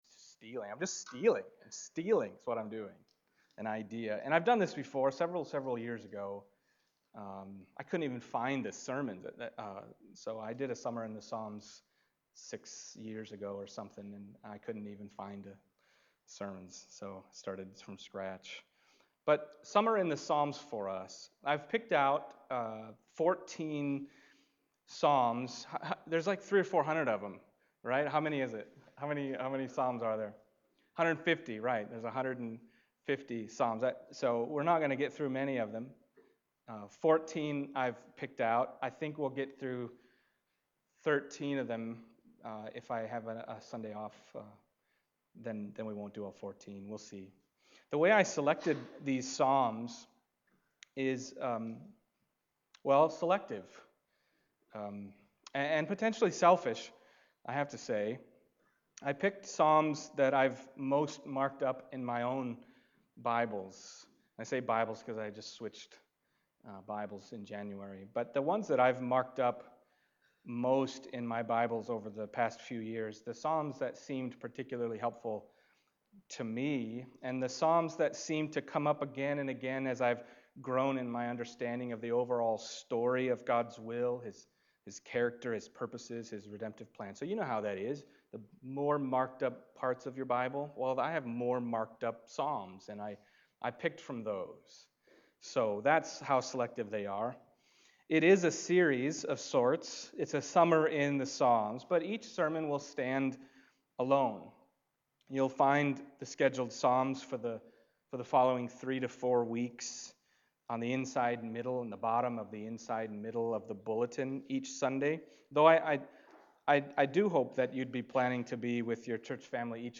Summer in the Psalms Passage: Psalm 1:1-6 Service Type: Sunday Morning « Evangelism